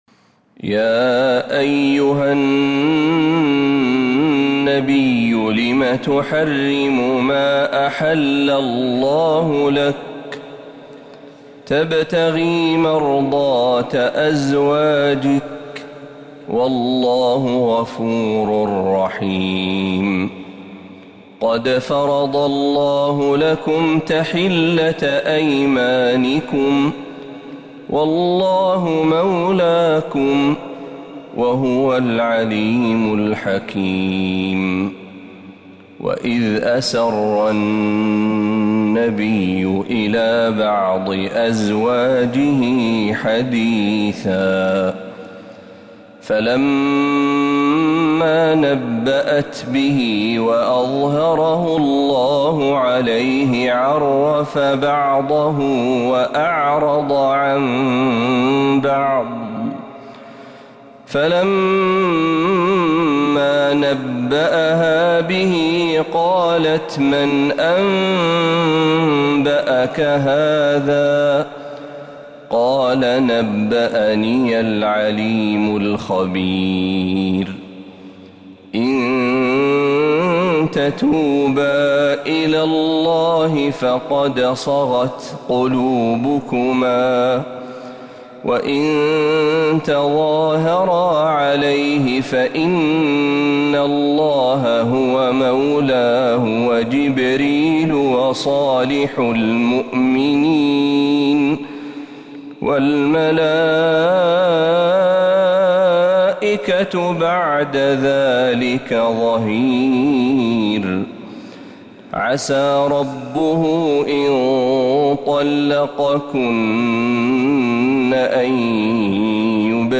سورة التحريم كاملة من الحرم النبوي > السور المكتملة للشيخ محمد برهجي من الحرم النبوي 🕌 > السور المكتملة 🕌 > المزيد - تلاوات الحرمين